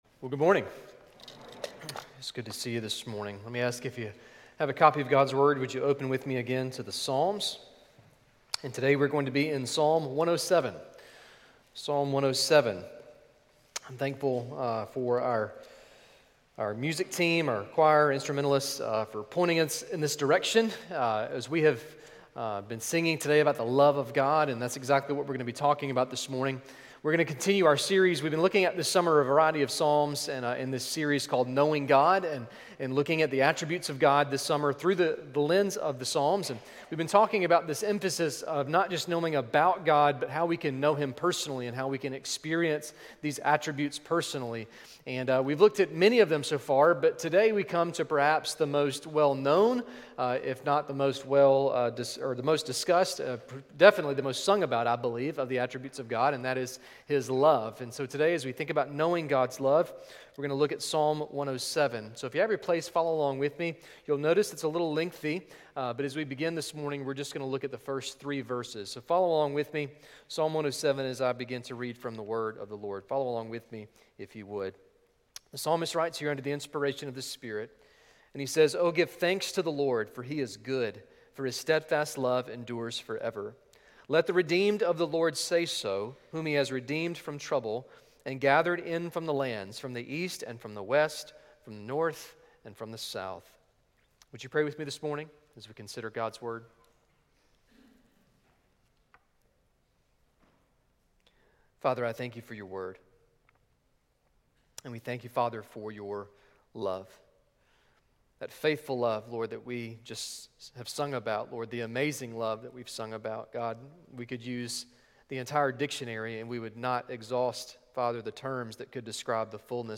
New Year's Resolution: Daily Bible Reading Sermon